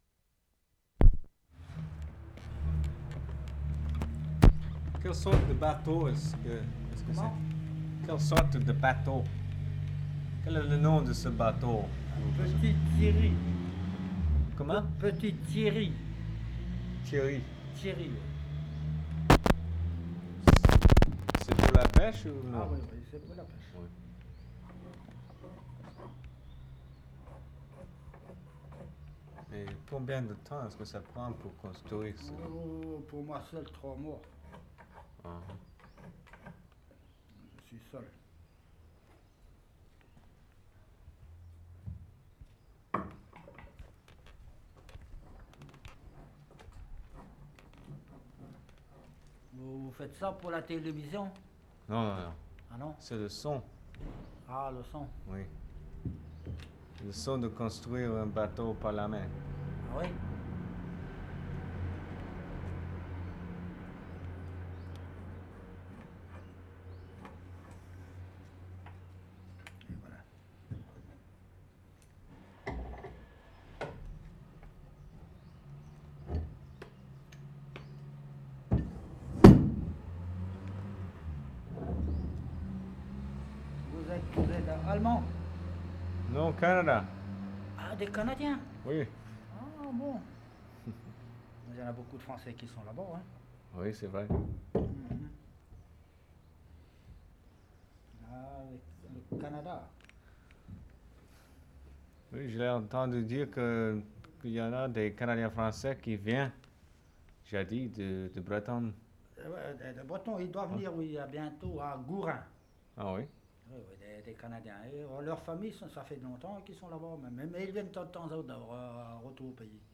Lesconil, France April 16/75
DIALOGUE WITH TWO BOAT BUILDERS